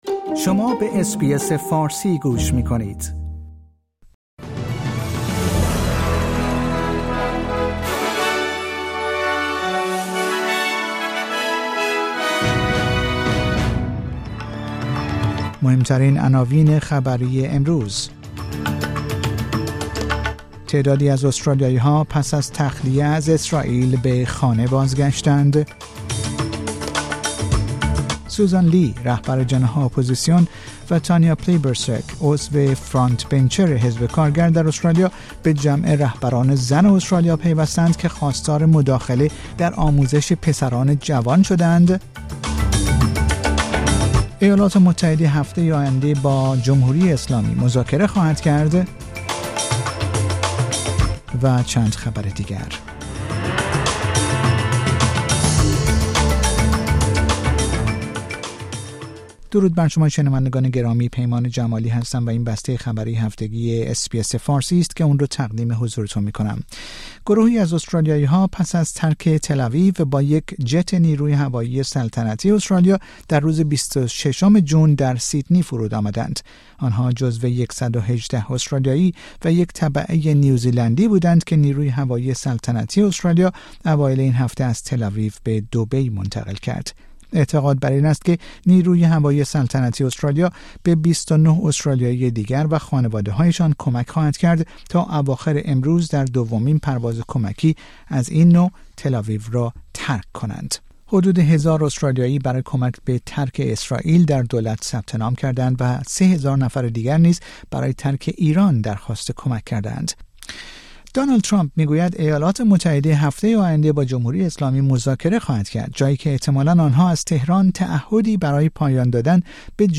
در این پادکست خبری مهمترین اخبار هفته منتهی به جمعه ۲۷ جون ارائه شده است.